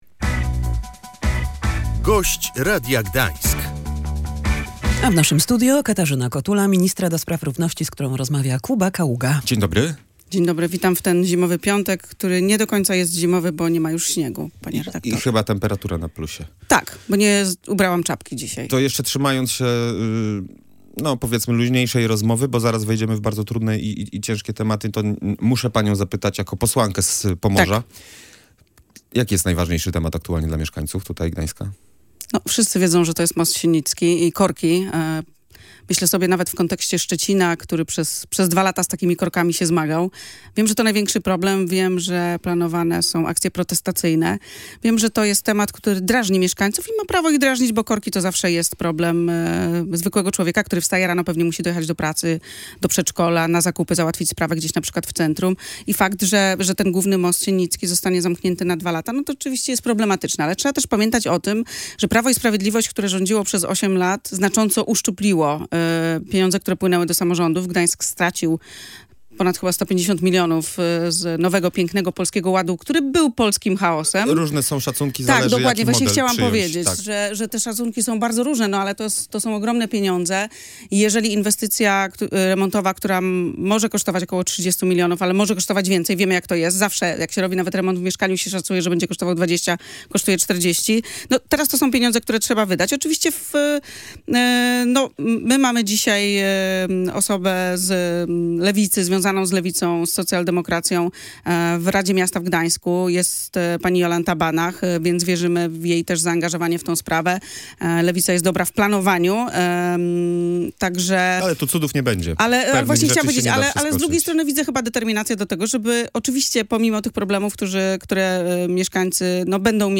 Zmiany w ustawie o przeciwdziałaniu przemocy w rodzinie zapowiedziała na antenie Radia Gdańsk Katarzyna Kotula, minister do spraw równości.